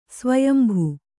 ♪ svayambhu